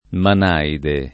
manaide [ man # ide ]